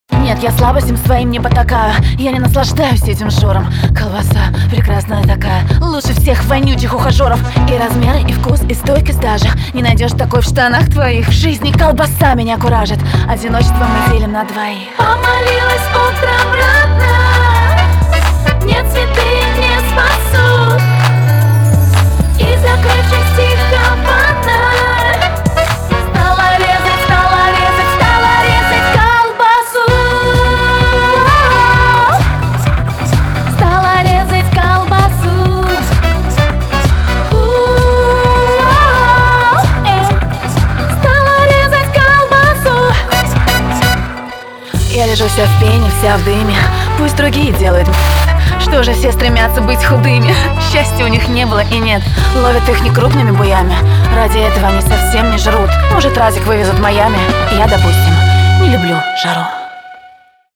• Качество: 320, Stereo
remix
смешные
с юмором